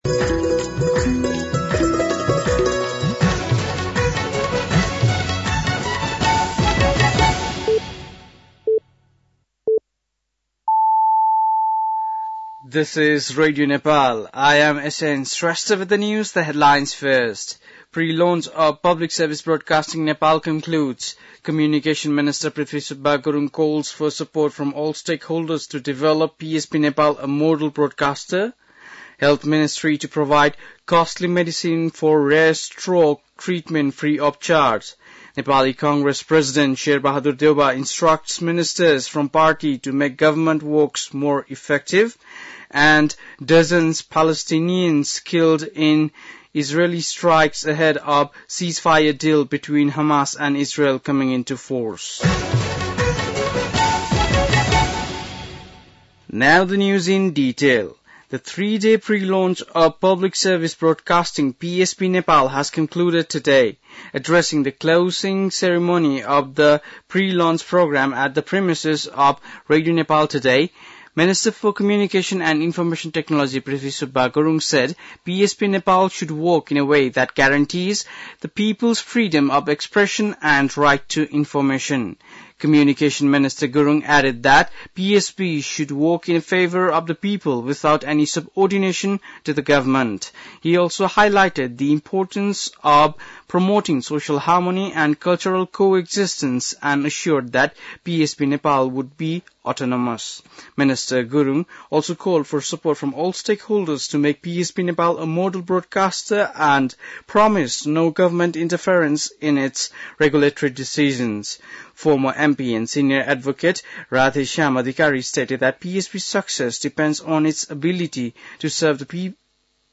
बेलुकी ८ बजेको अङ्ग्रेजी समाचार : ४ माघ , २०८१
8-PM-English-NEWS-10-03.mp3